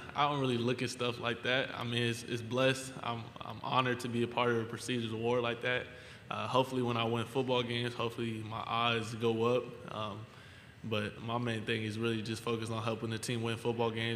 Moreover, Daniels was asked about the possibility of winning the Heisman Trophy. He humbly stated he isn’t looking into it and is just focused on winning games.